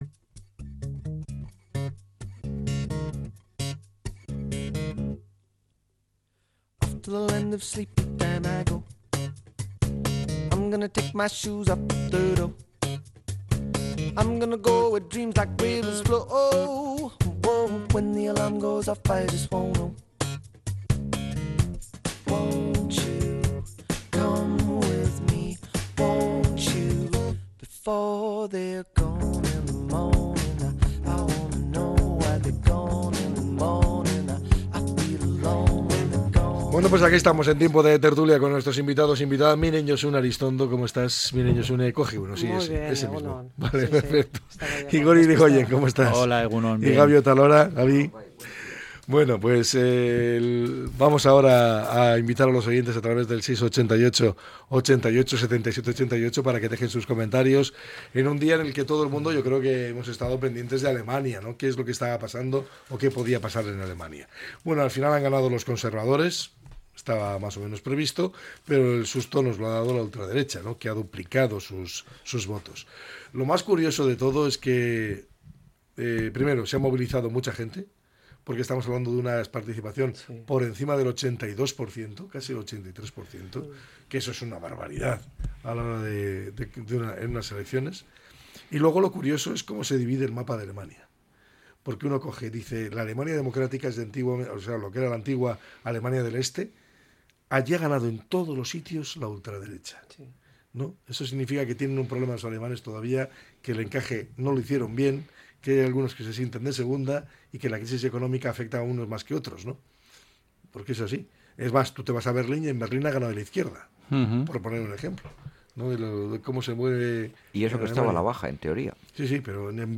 La tertulia 24-02-25.